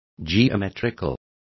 Complete with pronunciation of the translation of geometrical.